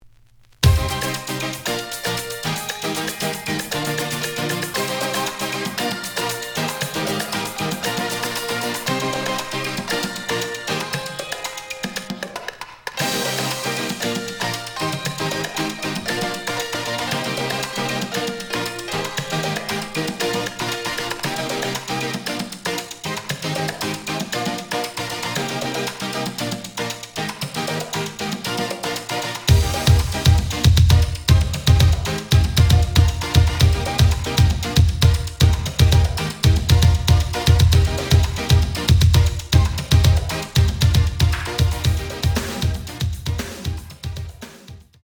(Latin Dub/Bonus Beats)
The audio sample is recorded from the actual item.
●Genre: House / Techno